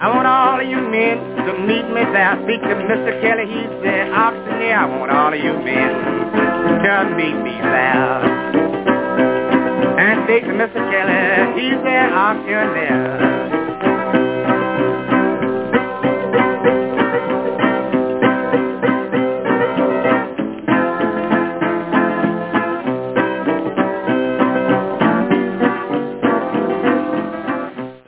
гитара-соло
вторая гитара
имя исполнителя на ложках неизвестно